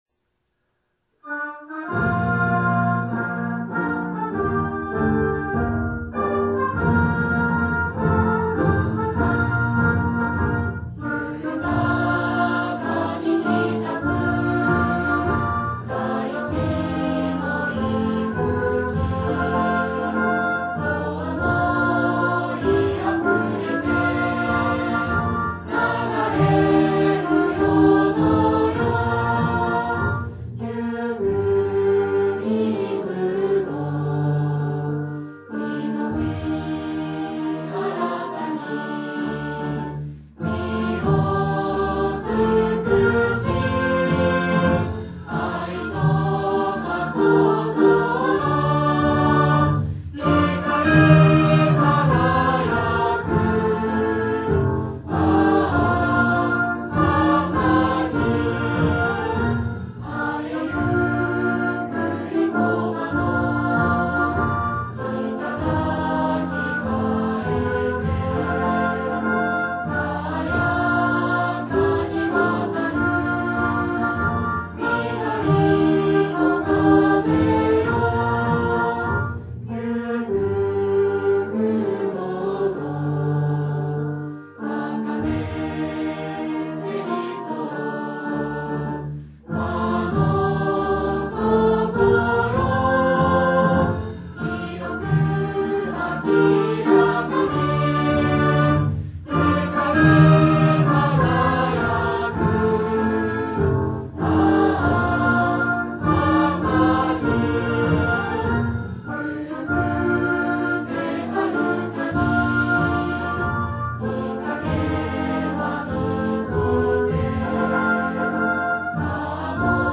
校歌
• 作詞／明珍 昇
• 作曲／片岡 通昭
再生（合唱＋吹奏楽） 再生（吹奏楽のみ）